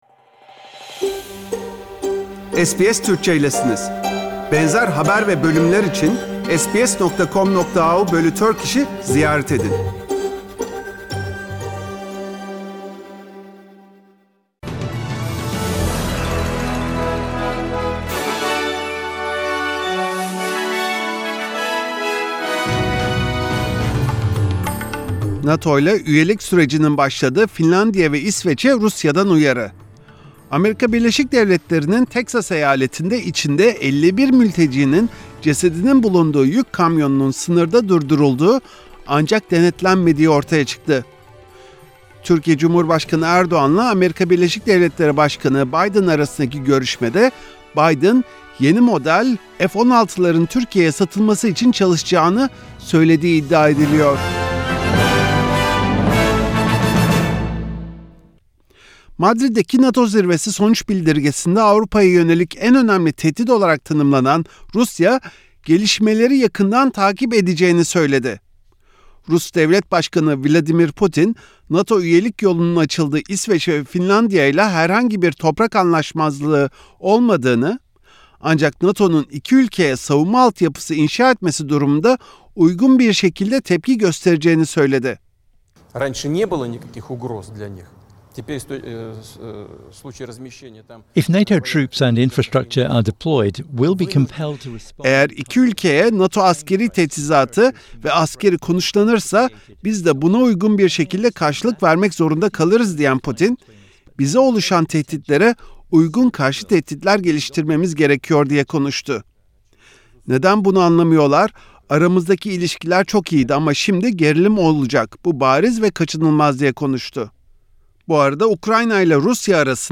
SBS Türkçe Haberler 30 Haziran